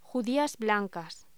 Locución: Judías blancas